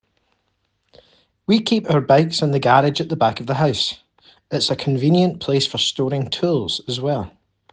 4. GB accent (Scotland): Garage